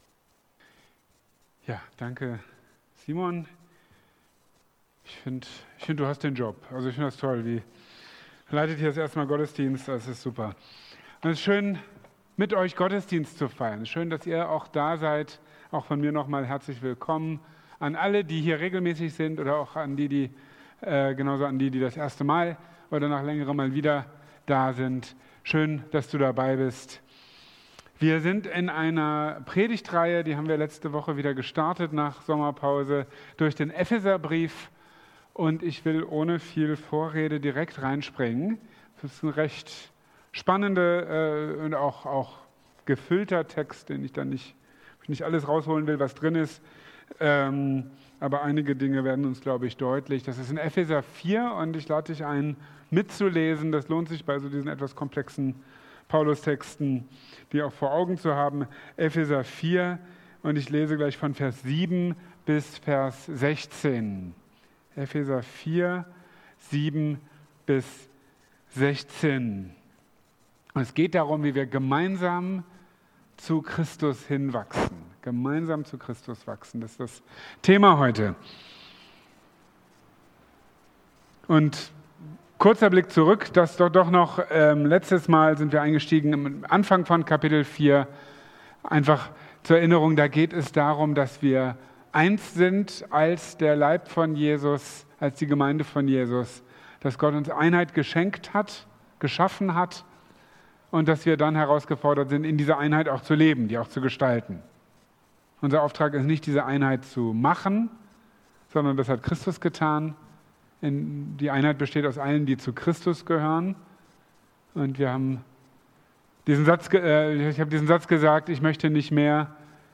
Gemeinsam zu Christus wachsen (Epheserbrief Teil 9) | Marburger Predigten